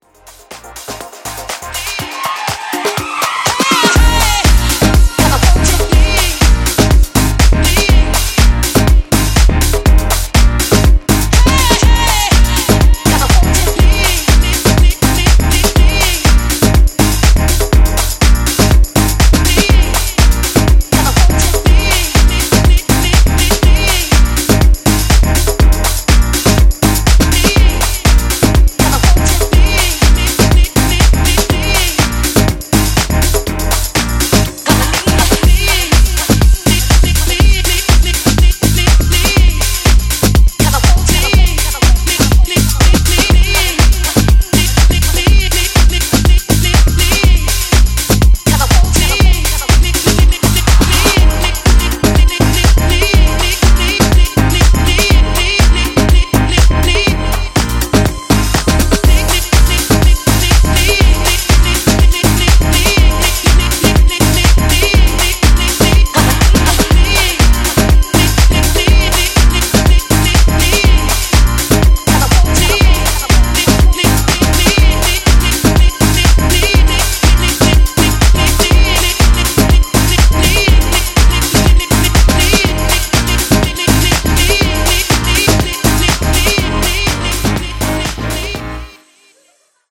原曲にさほど手を入れずによりDJプレイしやすく仕立てていった、今回も間違いなしの仕上がりとなっています。
ジャンル(スタイル) HOUSE